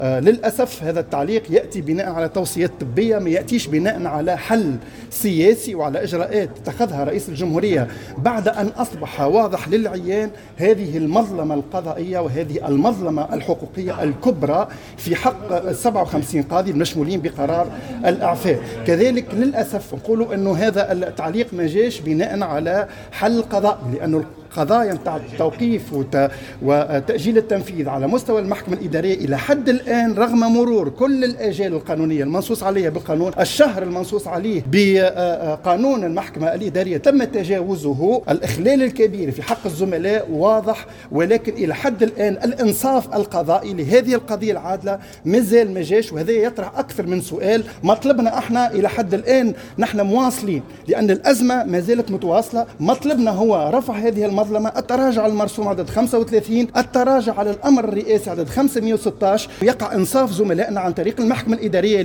ندوة صحفية حول إضراب جوع القضاة وإعفاء القضاة في ظل غياب الحل السياسي اليوم